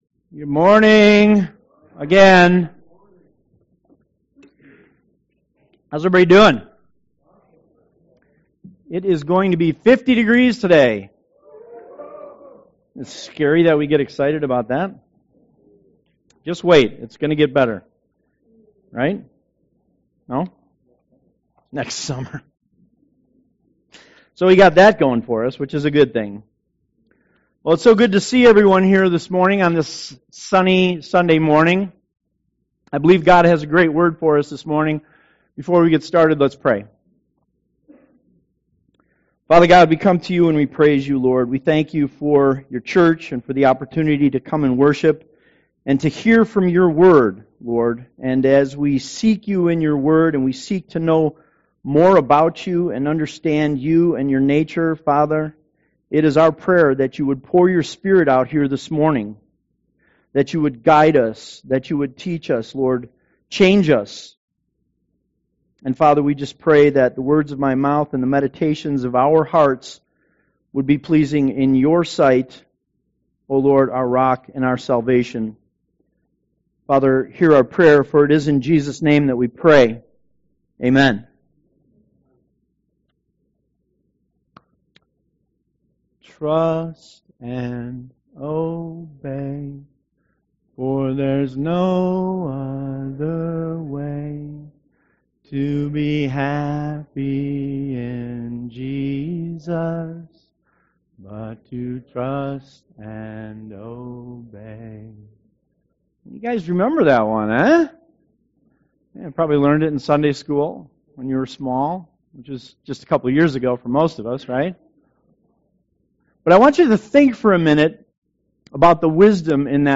Hope Community Church of Lowell's Sunday sermon audio's online for your convience.